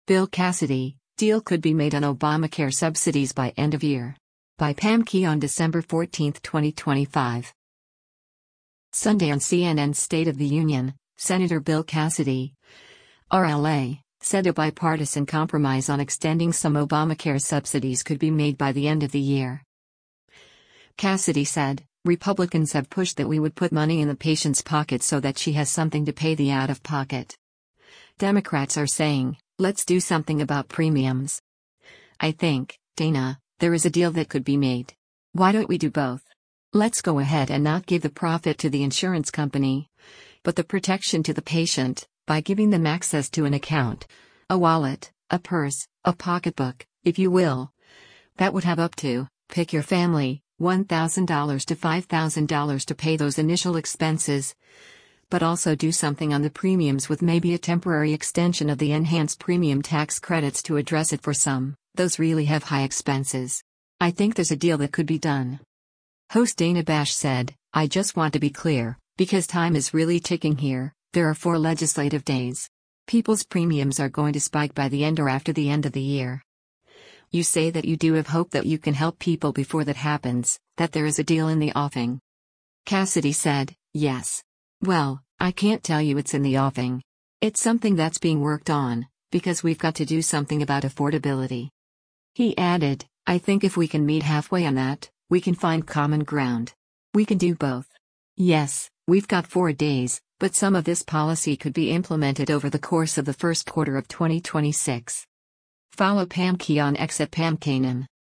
Sunday on CNN’s “State of the Union,” Sen. Bill Cassidy (R-LA) said a bipartisan compromise on extending some Obamacare subsidies could be made by the end of the year.